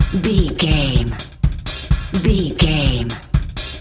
Electronic loops, drums loops, synth loops.,
Fast paced
Ionian/Major
Fast
industrial
driving
hypnotic